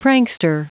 Prononciation du mot prankster en anglais (fichier audio)
Prononciation du mot : prankster